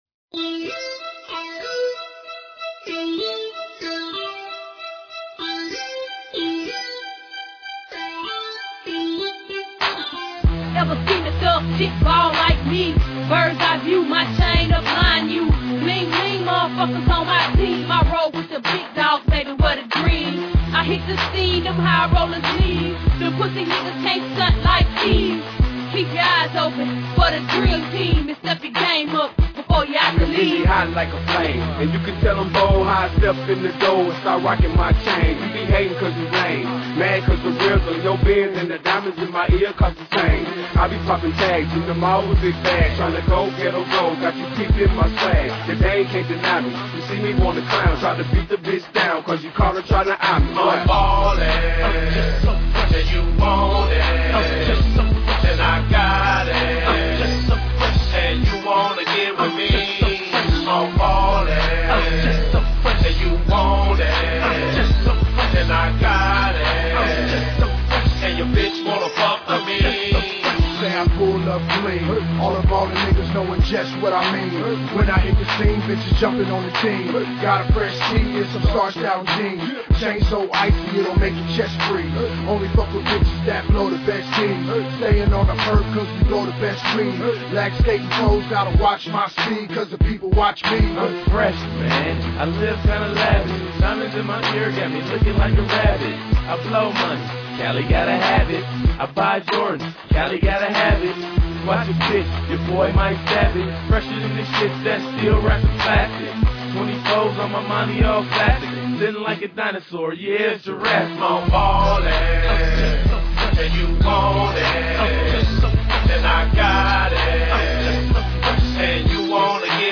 Tags: rap, mp3